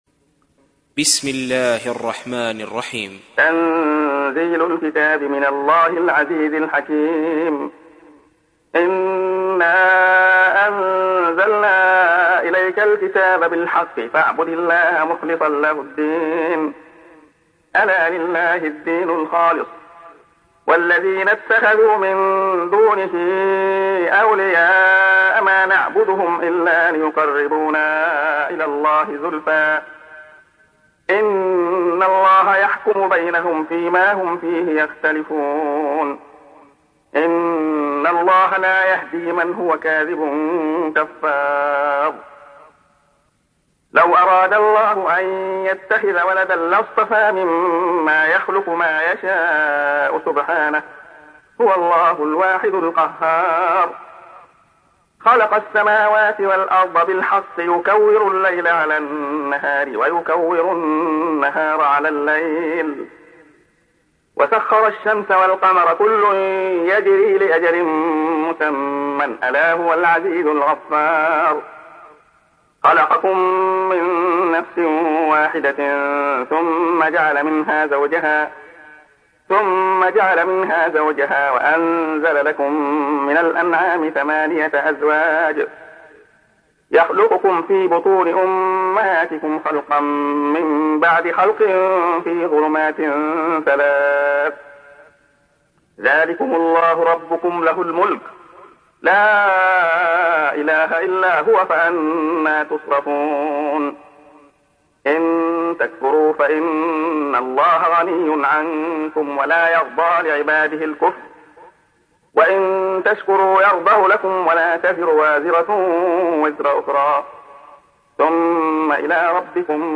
تحميل : 39. سورة الزمر / القارئ عبد الله خياط / القرآن الكريم / موقع يا حسين